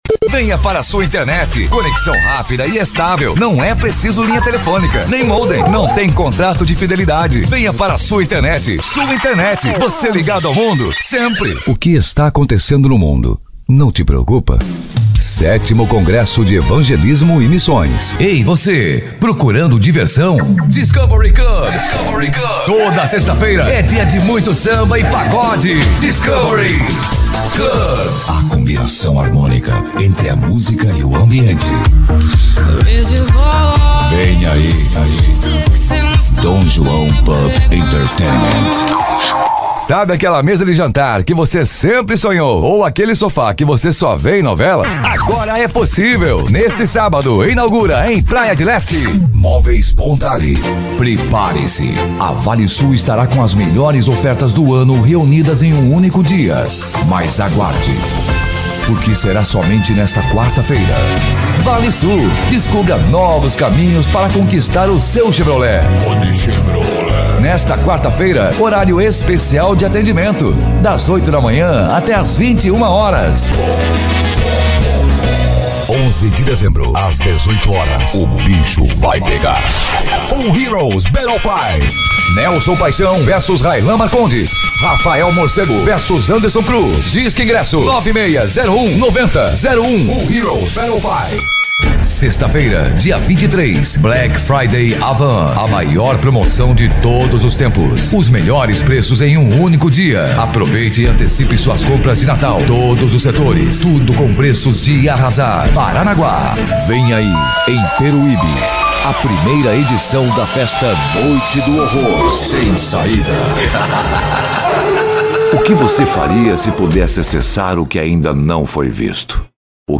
PADRÃO-INSTITUCIONAL-COLOQUIAL- IMPACTO-JOVEM-ANIMADO-PRA CIMA: